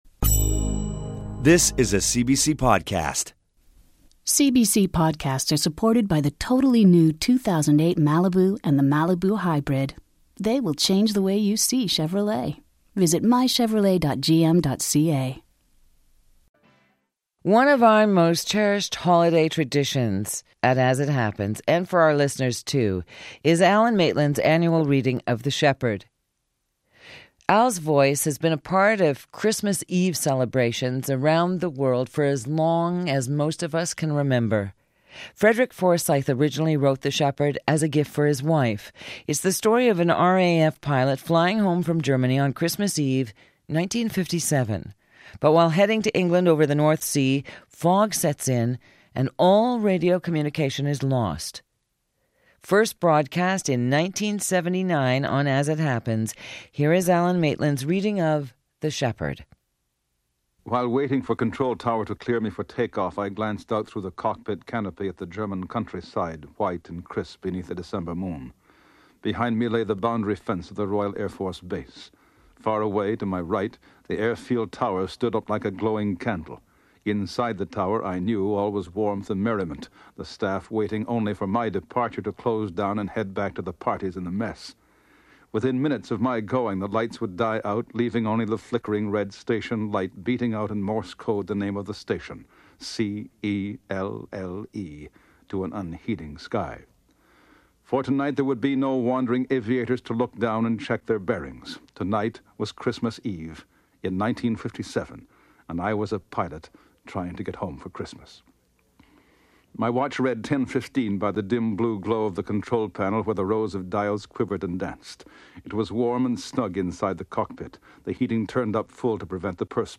The story was recorded in 1979 by one of Canada’s most beloved radio hosts, the late Alan Maitland.